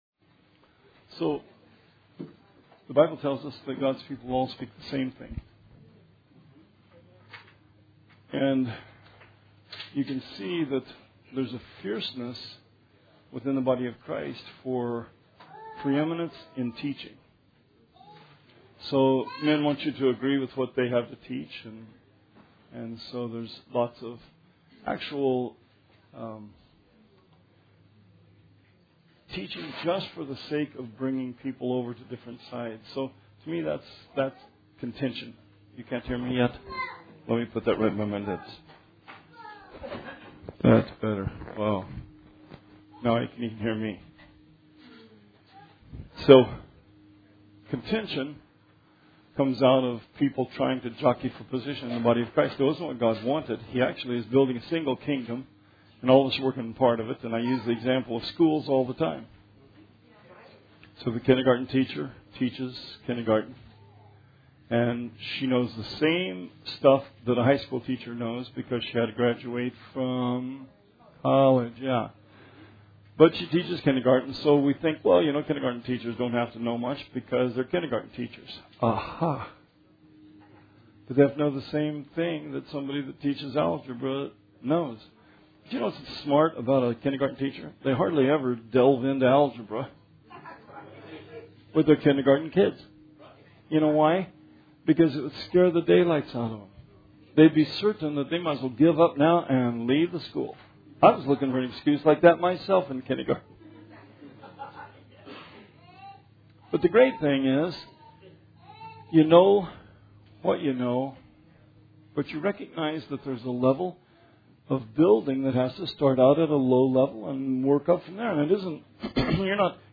Bible Study 5/24/17